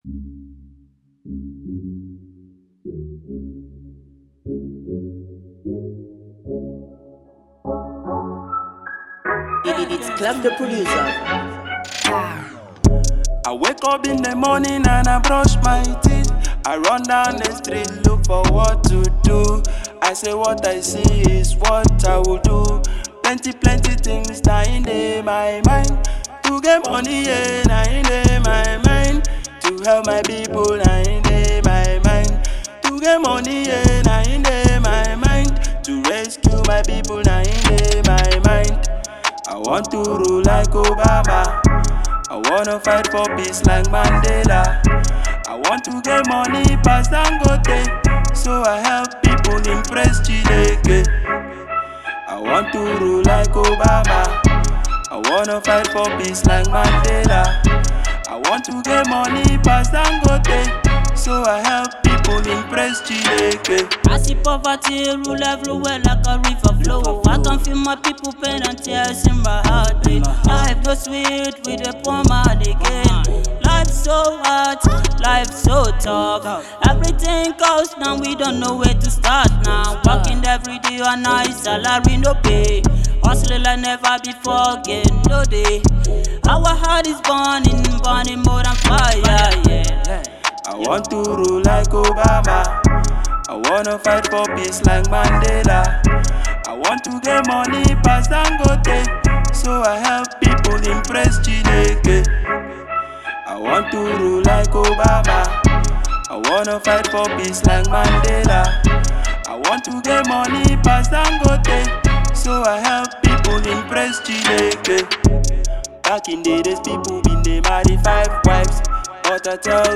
Another Inspiration song